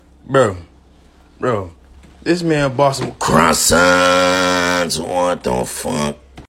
забавные
смешные
голосовые